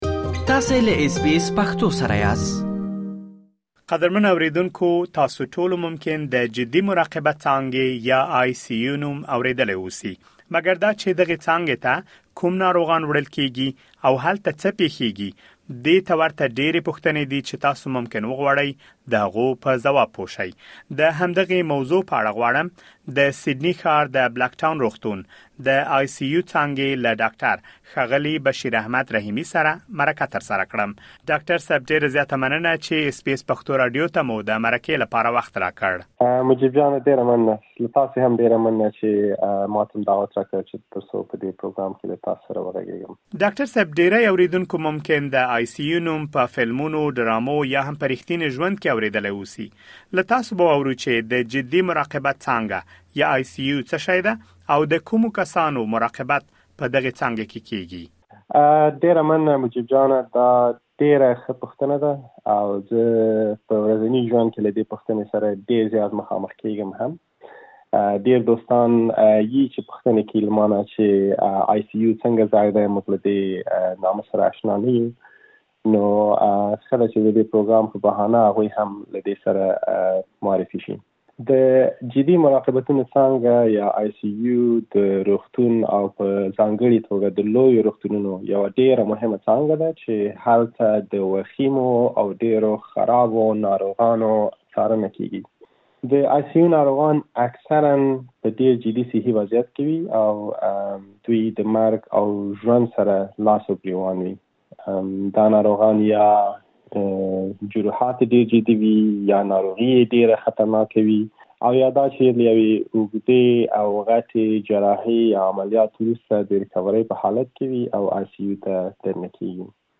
د جدي مراقبت څانګې (ICU) په اړه معلومات په مرکې کې اورېدلی شئ.